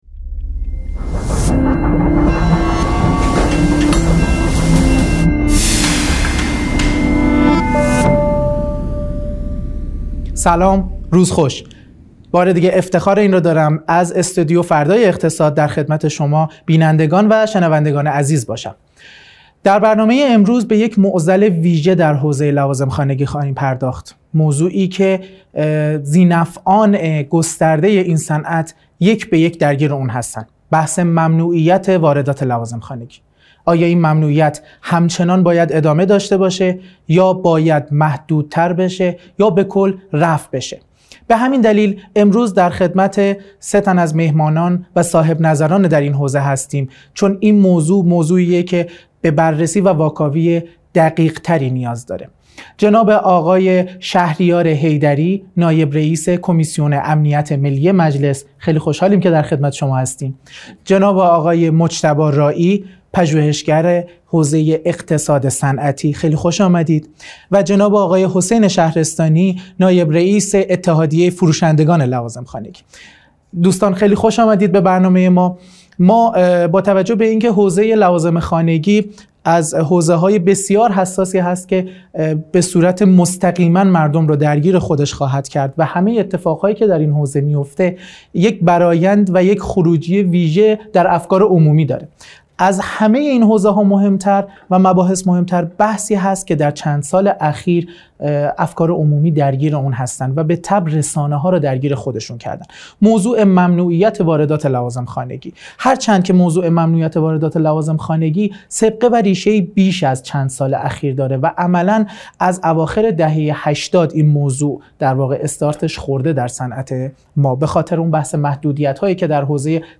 در جدیدترین برنامه فردای صنعت در استودیو «فردای اقتصاد»